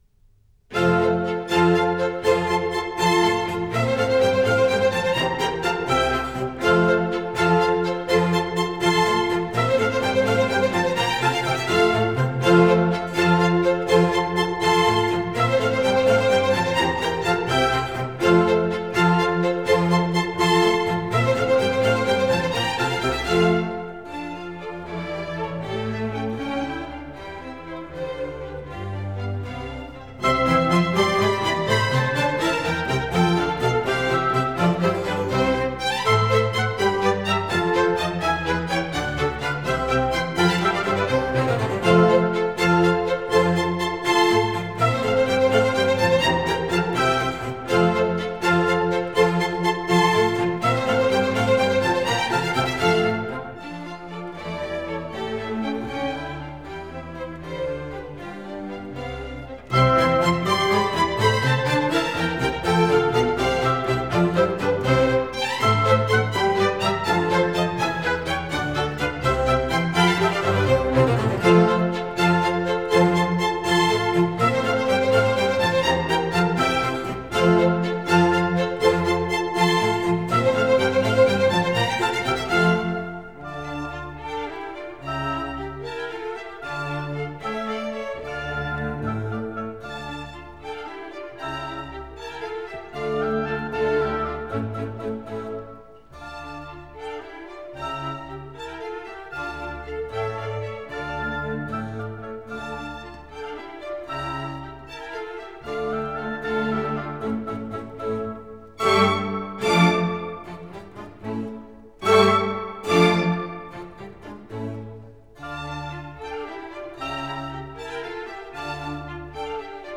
nhac-khong-loi